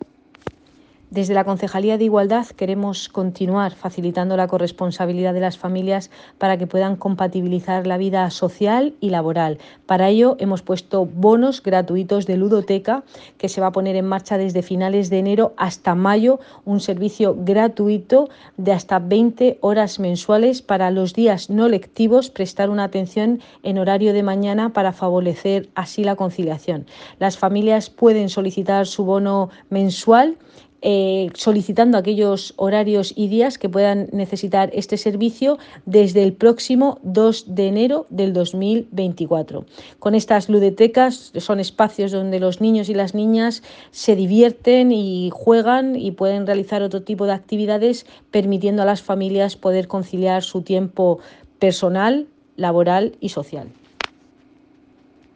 Enlace a Declaraciones de Cristina Mora.